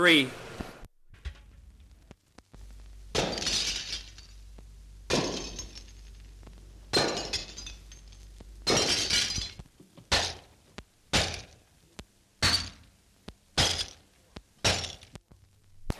老式撞车 " G2603灯泡断裂
描述：许多不同的灯泡断裂，碰撞，撞击，不同的球场。 这些是20世纪30年代和20世纪30年代原始硝酸盐光学好莱坞声音效果的高质量副本。 40年代，在20世纪70年代早期转移到全轨磁带。我已将它们数字化以便保存，但它们尚未恢复并且有一些噪音。